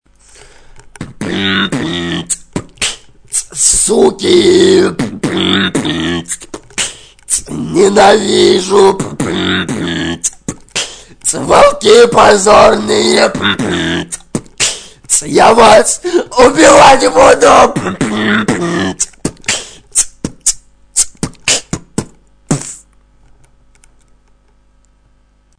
ничего хуже чем мат в битах, не придумаешь...